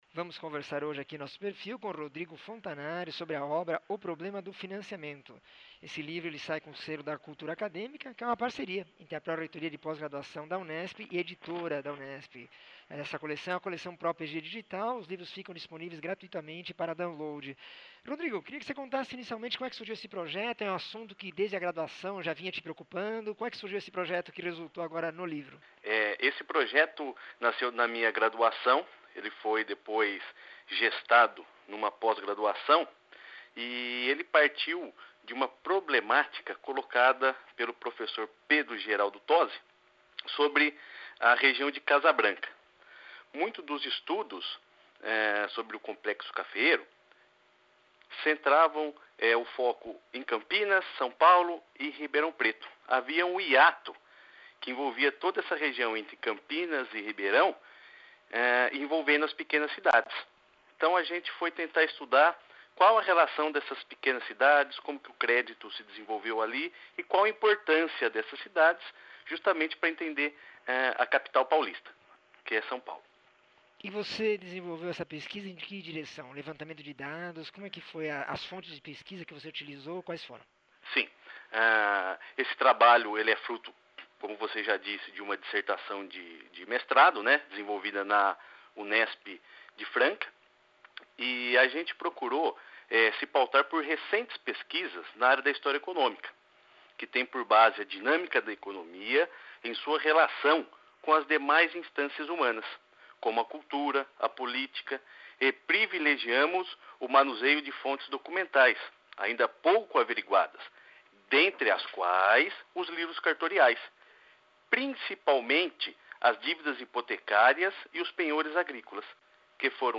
entrevista 1706